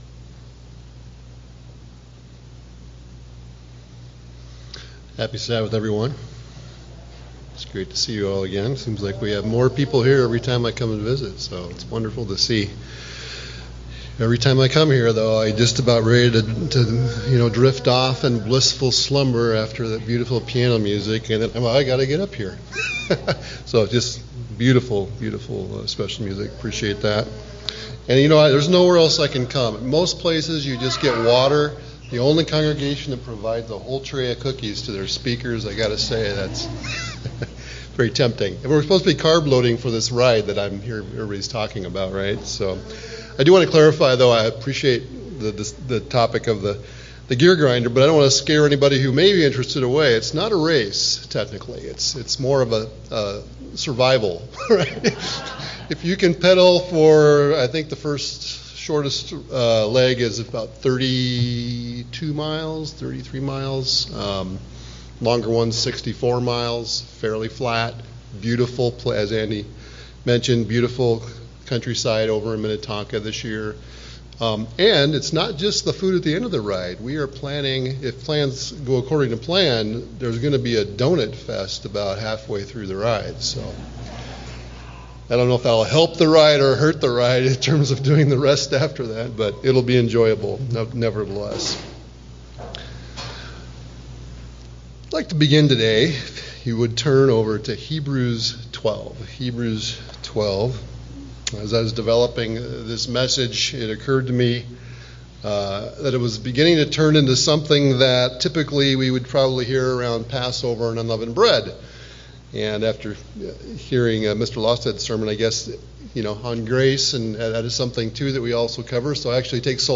In this rich and encouraging message, we’re invited to examine where we truly “stand” in our relationship with God—before the fear-filled mountain of Sinai or the grace-filled mountain of Zion. Using Hebrews 12 as the central text, the sermon paints a vivid contrast between Israel’s old covenant experience rooted in dread, distance, and repeated sacrifices, and the new covenant reality God calls us into today—a life marked by hope, access, love, and transformation.